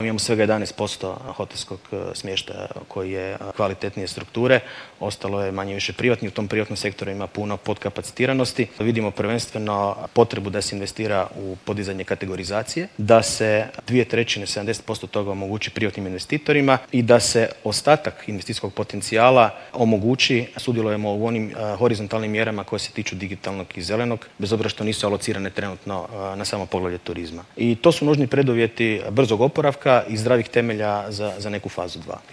ZAGREB - Ususret donošenju Nacionalnog plana za oporavak i otpornost u organizaciji HUP-a održana je konferencija ''Kakve nas investicije mogu izvući iz krize'' na kojoj su sugovornici koji dolaze iz realnih sektora govorili o preduvjetima potrebnim za oporavak od krize uzrokovane pandemijom koronavirusa, kao i o tome što je domaćoj industriji potrebno da dosegne svoj puni investicijski potencijal.